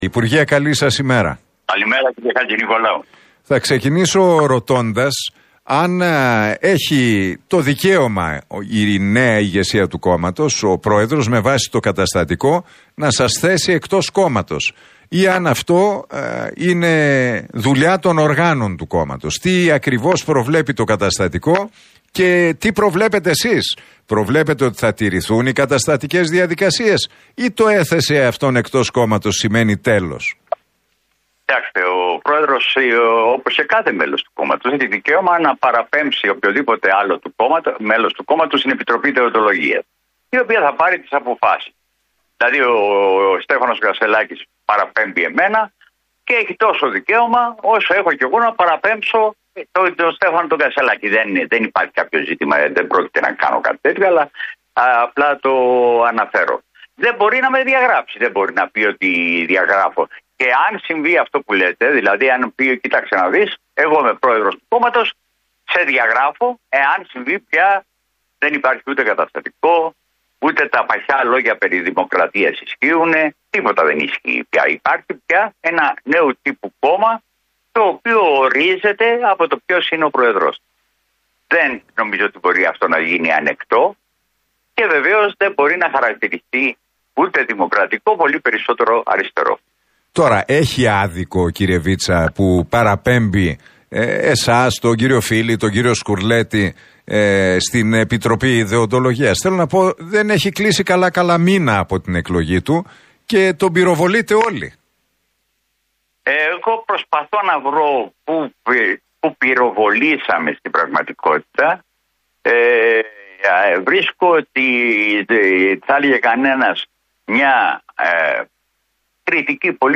Δεν μπορεί να με διαγράψει, δεν μπορεί να πει τον διαγράφω» δήλωσε ο Δημήτρης Βίτσας, μιλώντας στον Νίκο Χατζηνικολάου στον Realfm 97,8.